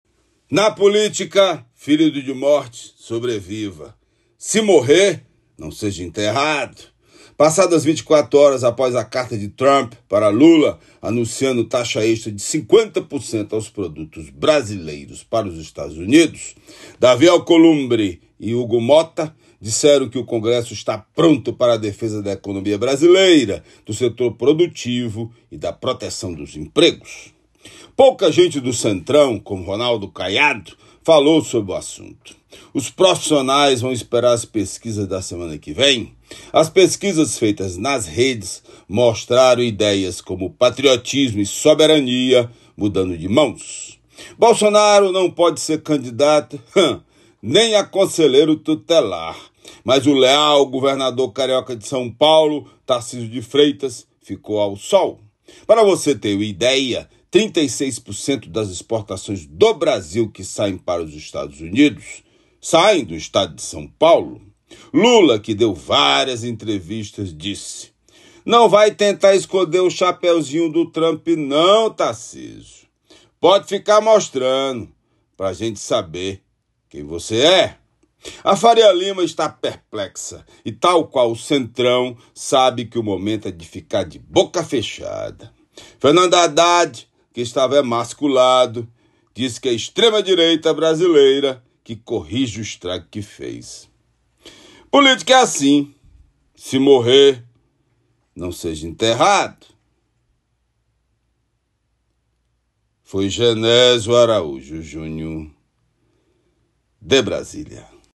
Comentário